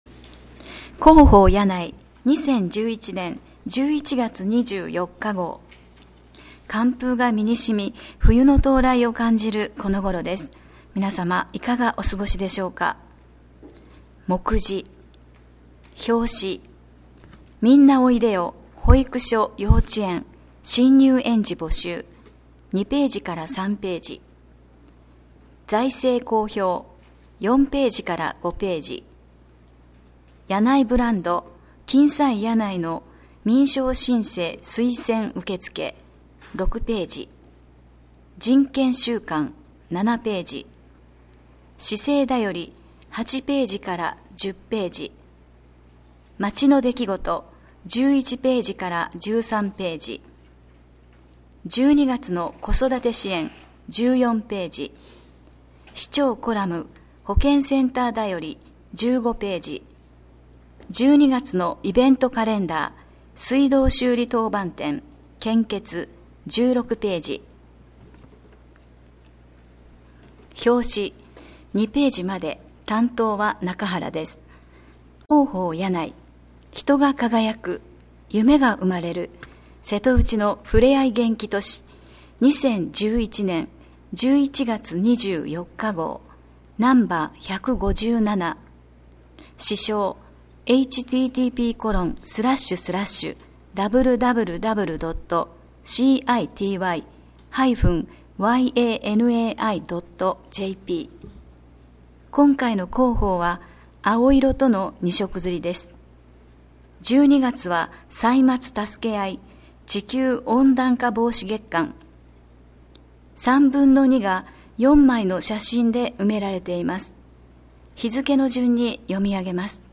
声の広報（音訳版：発行後1週間程度で利用可能）はこちらから [mp3／44.27MB]